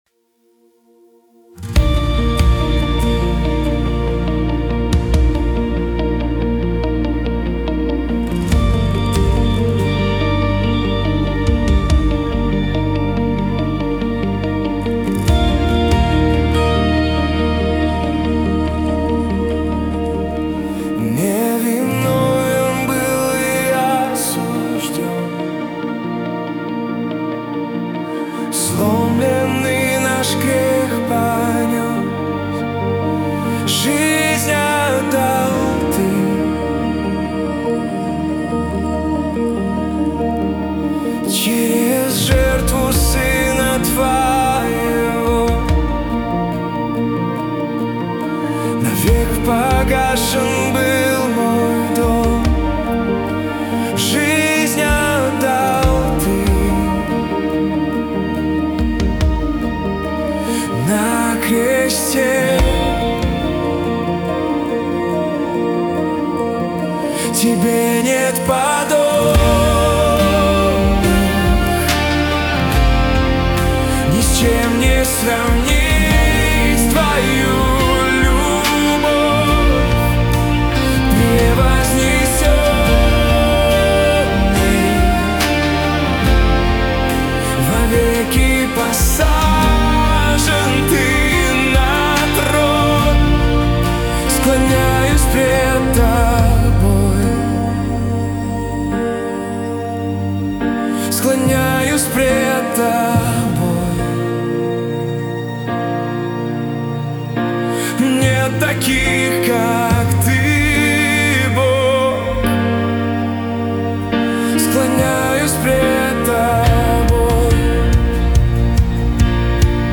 песня ai
258 просмотров 57 прослушиваний 2 скачивания BPM: 142